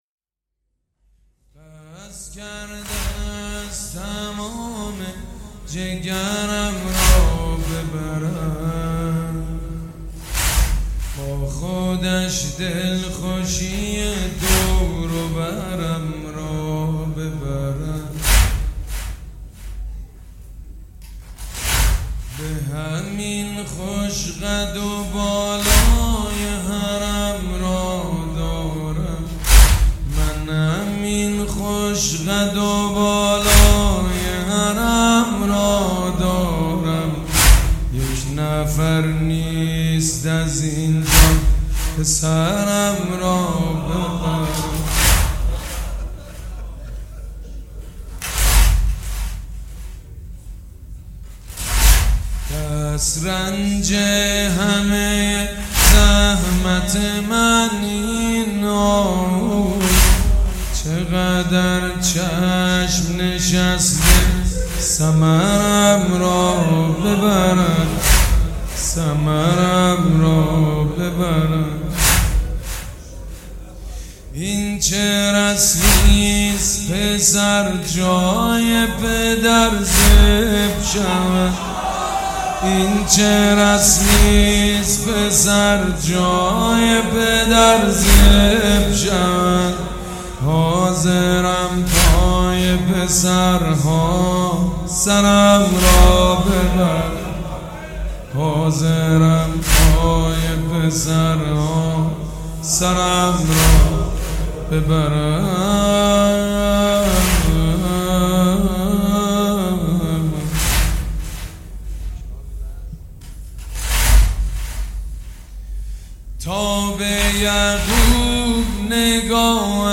سید مجید بنی فاطمه